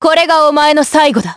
Seria-Vox_Skill1_jp.wav